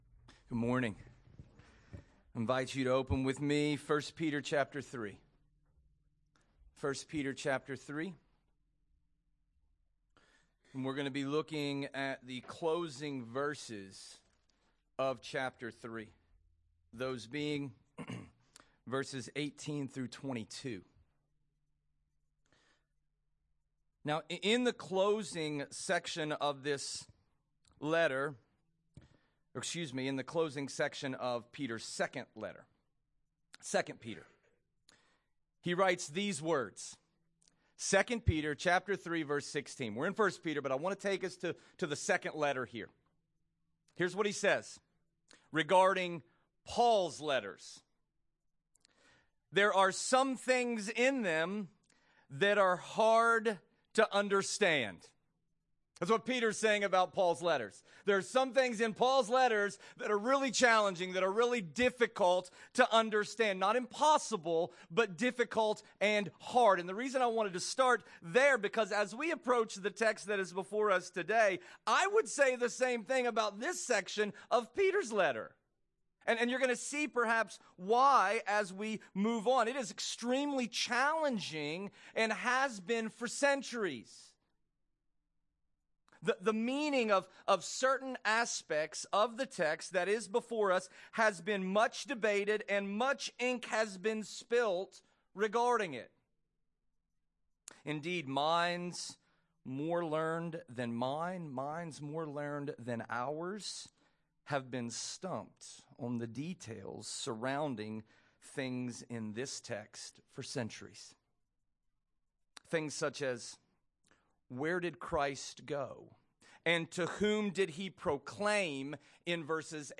Nov 10, 2024 Encouragement in Suffering (11/10/2024) MP3 SUBSCRIBE on iTunes(Podcast) Notes Discussion Sermons in this Series 1 Peter 3:18-22 Loading Discusson...